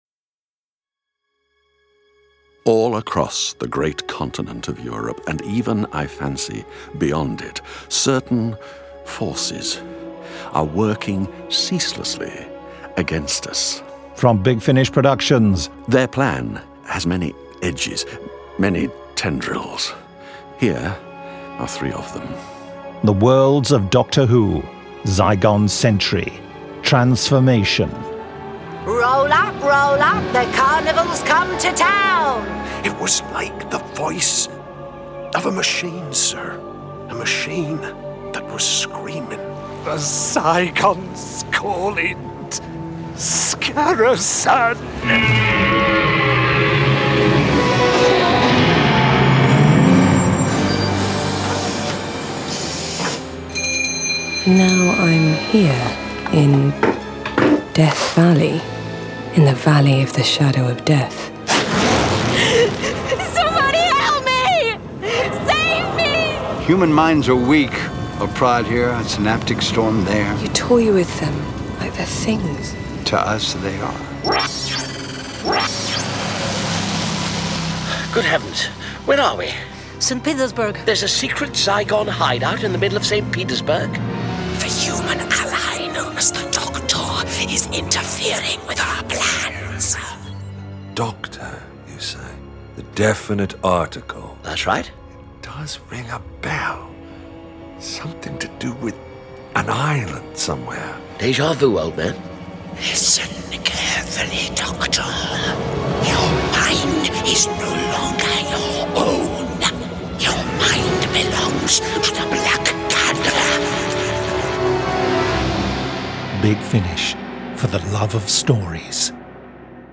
Award-winning, full-cast original audio dramas from the worlds of Doctor Who, Torchwood, Blake's 7, Class, Dark Shadows, Avengers, Omega Factor, Star Cops, Sherlock Holmes, Dorian Gray, Pathfinder Legends, Prisoner, Adam Adamant Lives, Space 1999, Timeslip, Terrahawks, Space Precinct, Thunderbirds, Stingray, Robin Hood, Dark Season, UFO, Stargate, V UK, Time Tunnel, Sky, Zygon Century, Planet Krynoid, Turpin, Young Bond